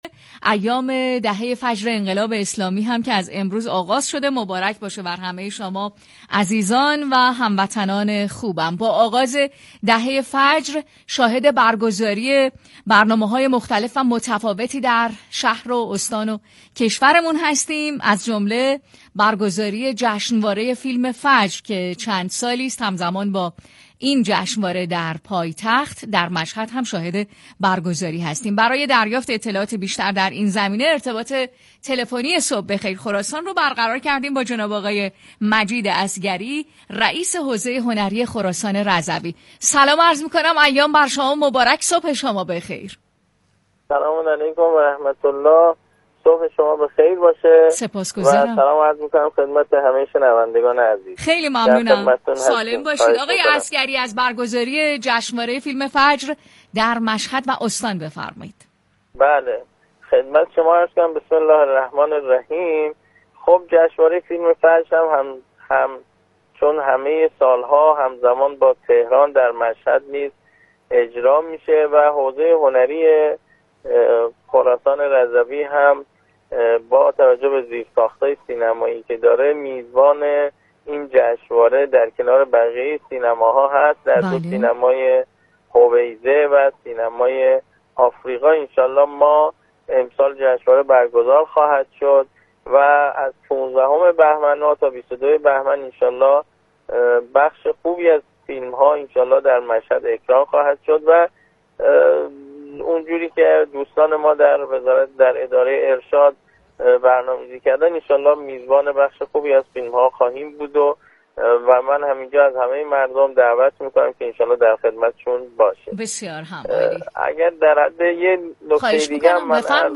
همزمان با آغاز جشنواره فیلم فجر